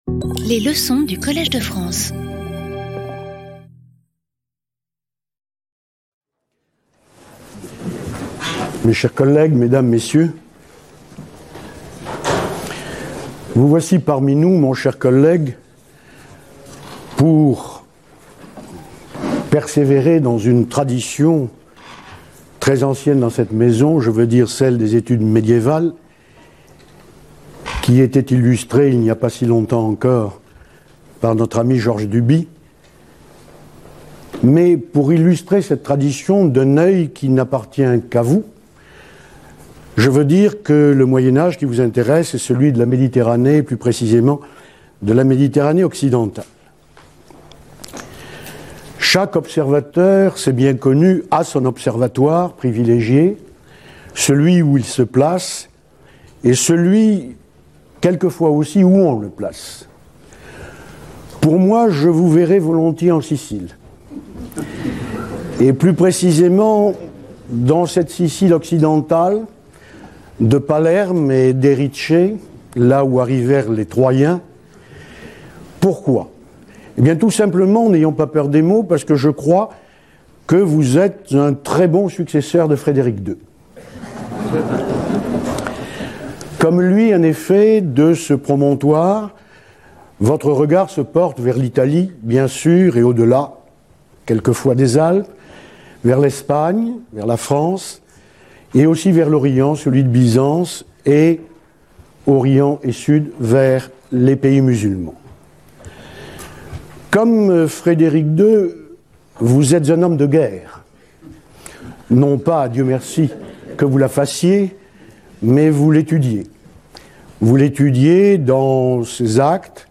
Pierre Toubert, pour commencer sa leçon inaugurale, raconte l'histoire du conférencier peu disert, tirée d'un texte du Xe siècle qui circulait à Cordoue. Cette histoire fut traduite au XIIe siècle de l'arabe au latin, puis passa en Italie.